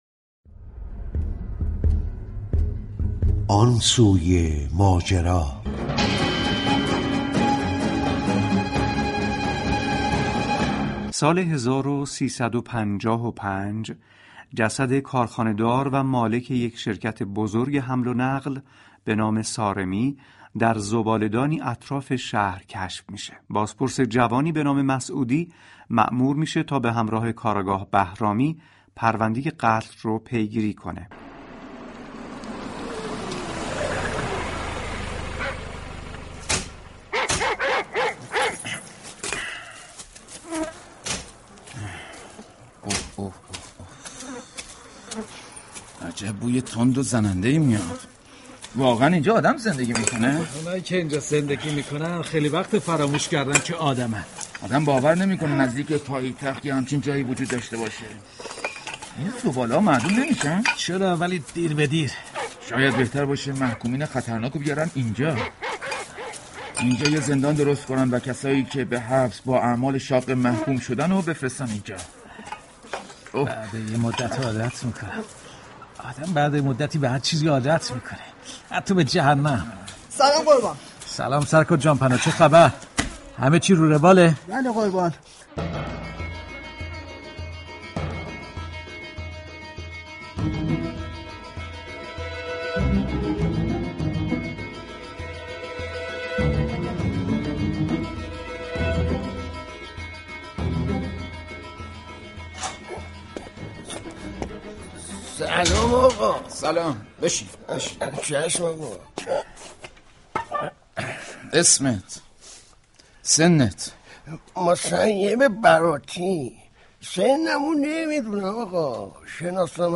پخش سریال جدید پلیسی